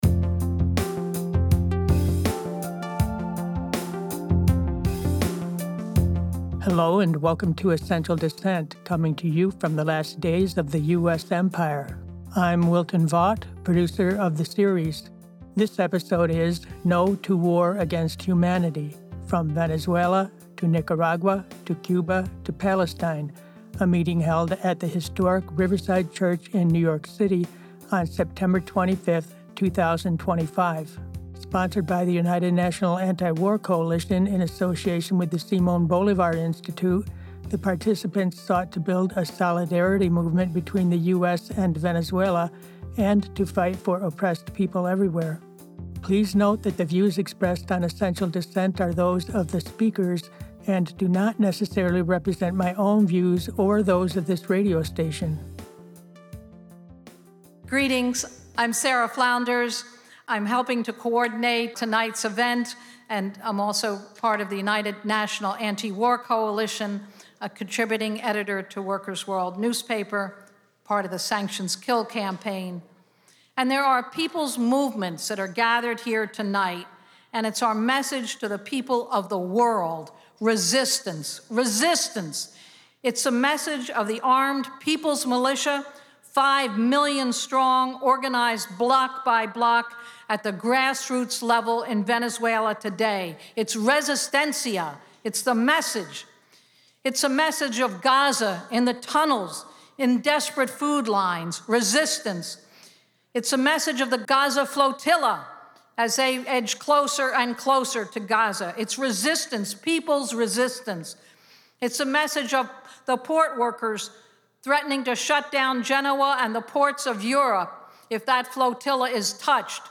No To War Against Humanity! Subtitle: From Venezuela to Nicaragua to Cuba to Palestine! Program Type: Action/Event Speakers